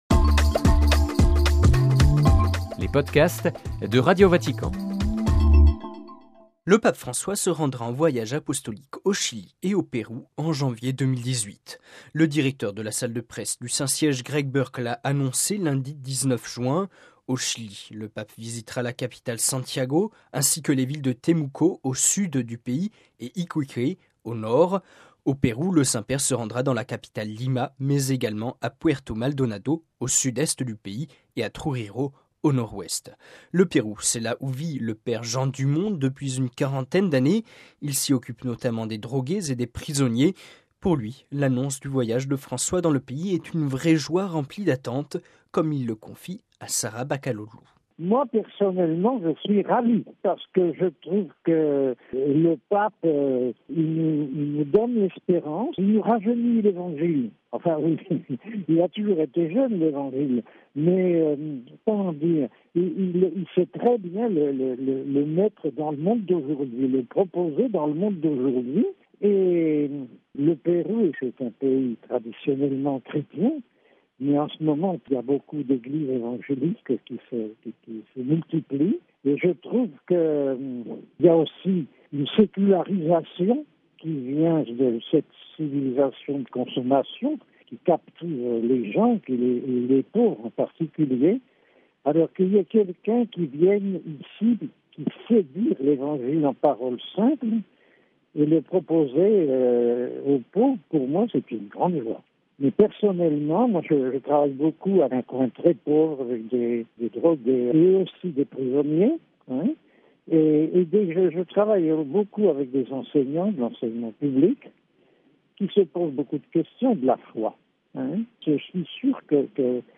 (RV) Entretien - Le Pape François effectuera un voyage apostolique au Chili et au Pérou en janvier 2018, a annoncé lundi 19 juin 2017 la salle de presse du Saint-Siège.